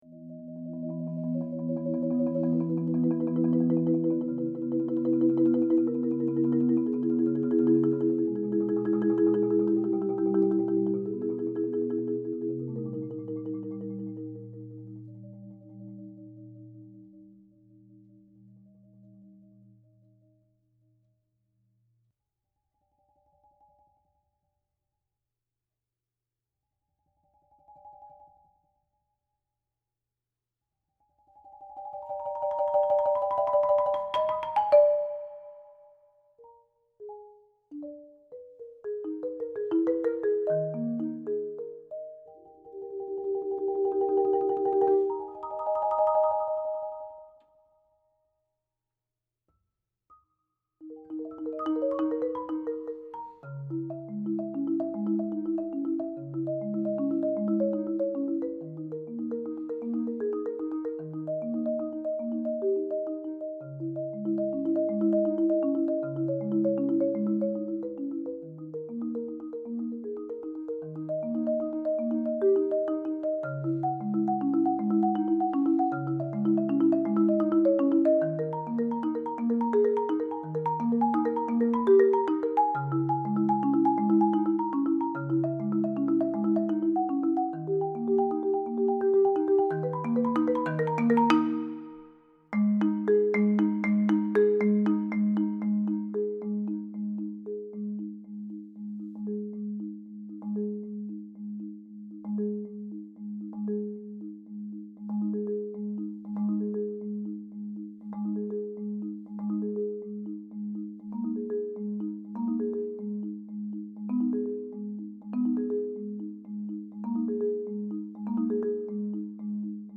Marimba Solo